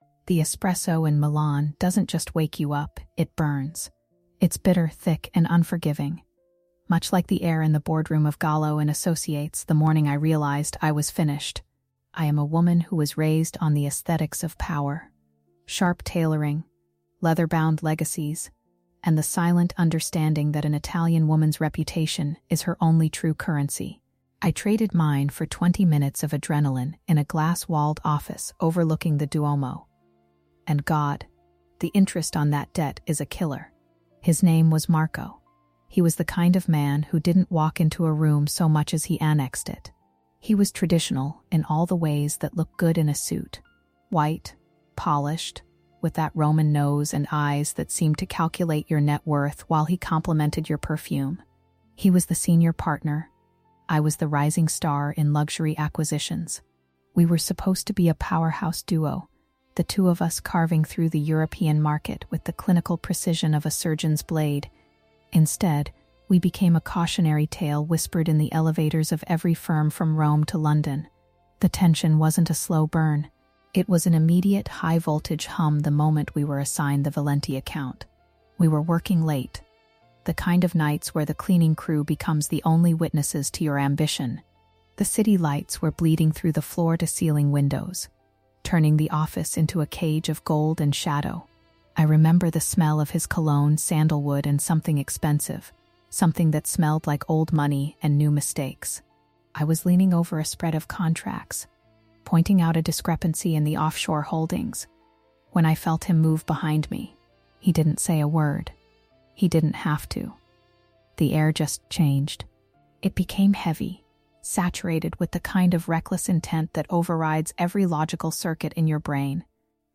Our narrator, a sharp and successful Italian professional, recounts the moment her career trajectory collided with the magnetic presence of Marco, a Senior Partner at Gallo & Associates.